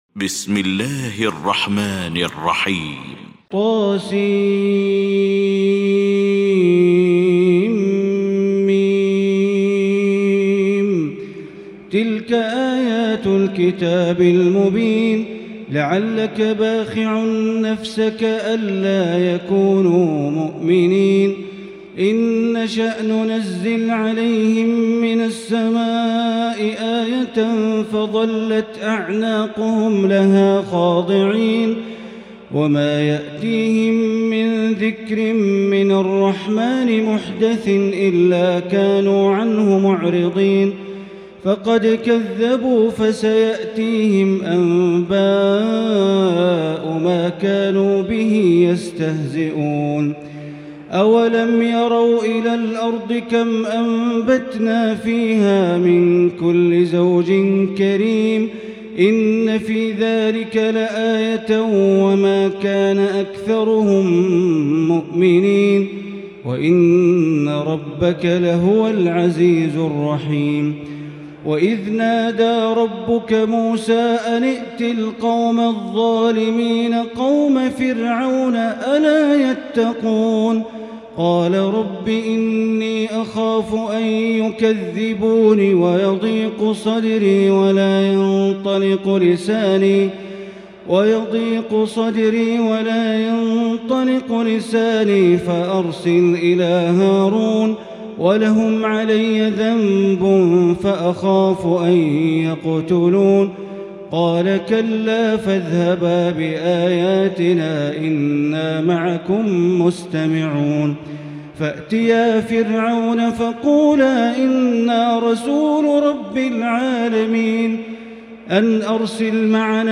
المكان: المسجد الحرام الشيخ: معالي الشيخ أ.د. بندر بليلة معالي الشيخ أ.د. بندر بليلة الشعراء